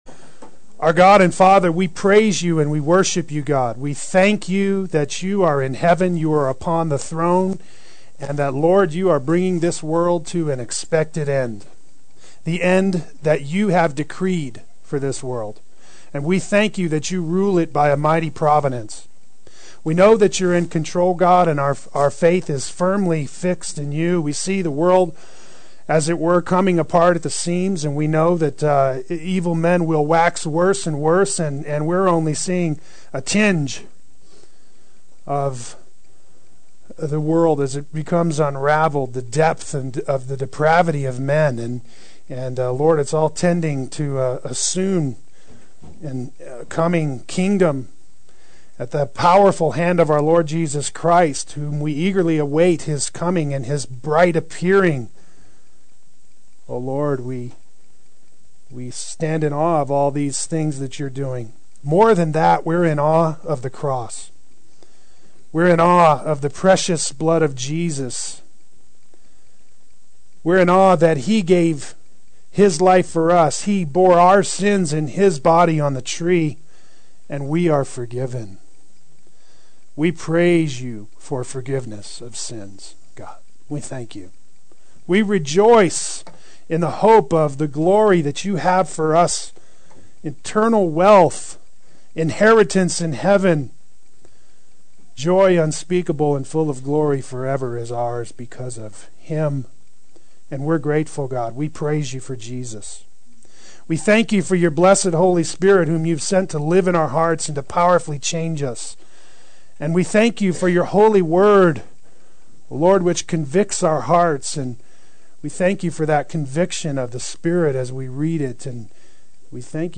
Play Sermon Get HCF Teaching Automatically.
Walk By the Spirit Adult Sunday School